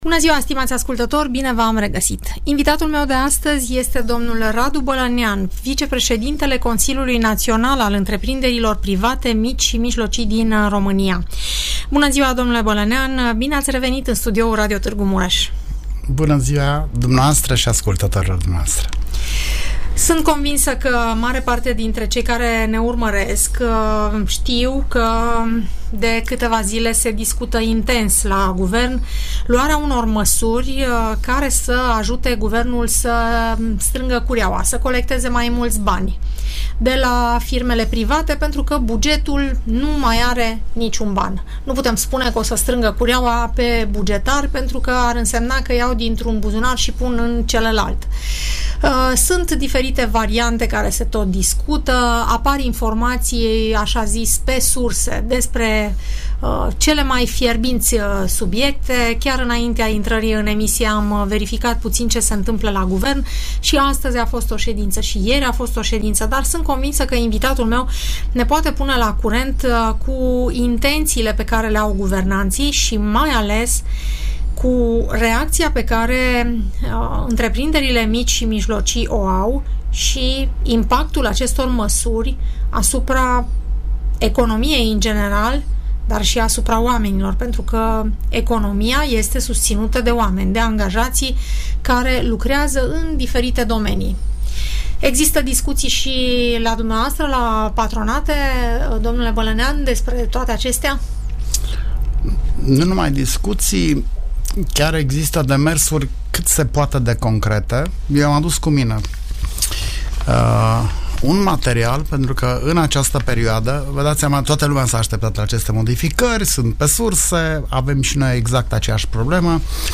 Coaliția PSD-PNL urmează să introducă în sfera fiscală și în cea de taxare, câteva modificări care sunt discutate în emisiunea „Părerea ta”, difuzată la Radio Tg Mureș.